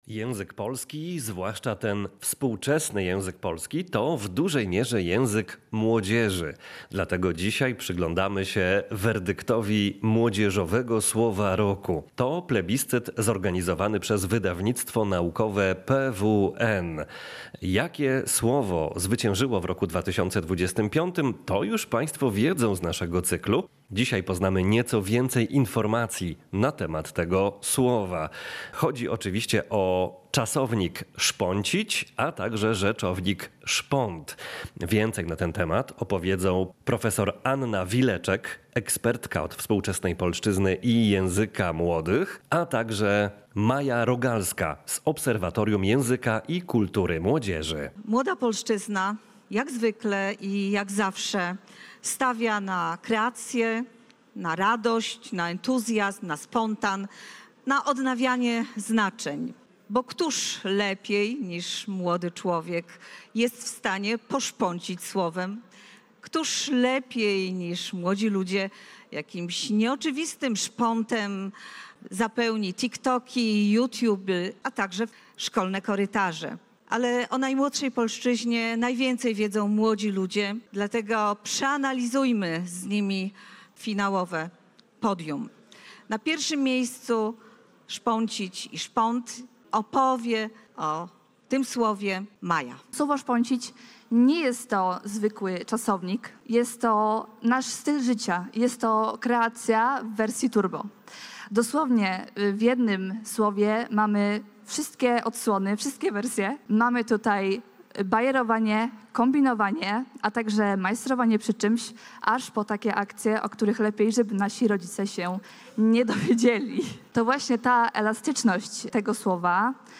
Posłuchaj relacji dźwiękowej z gali „Młodzieżowe Słowo Roku”